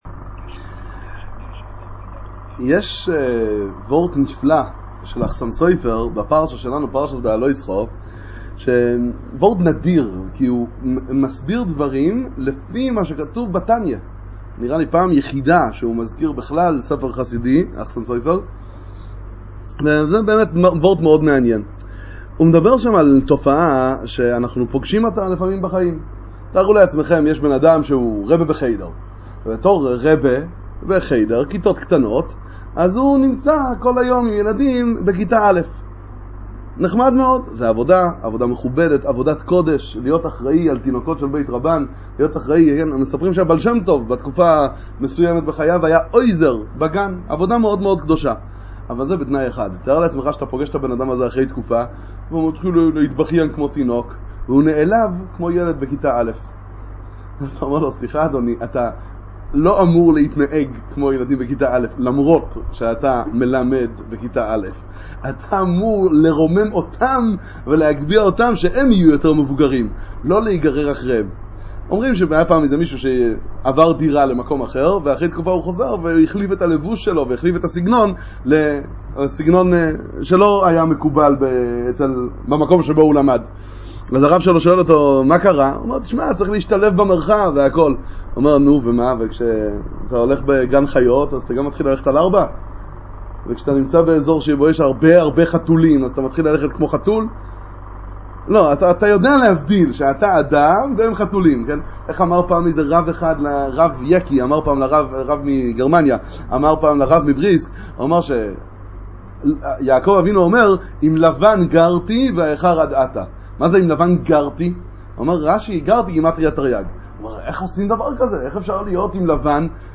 שיעור בספרי אדמו"ר הזקן